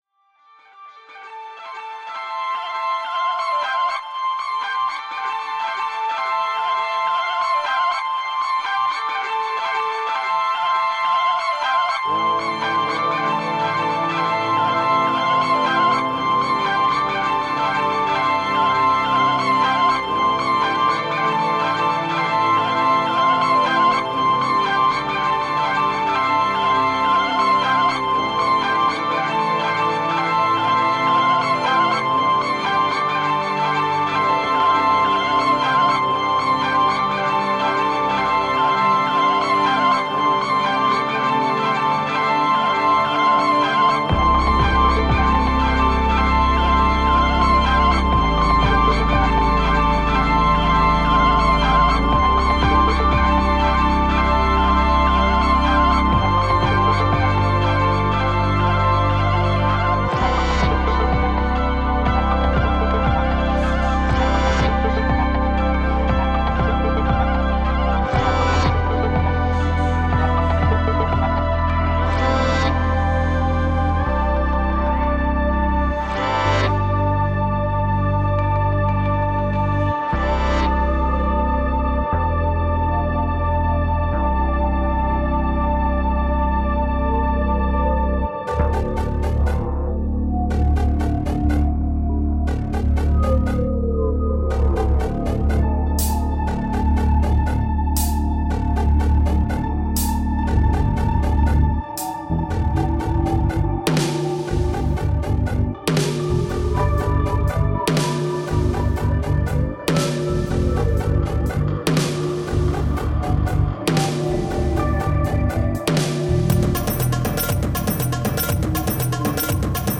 Ambient Trance Prog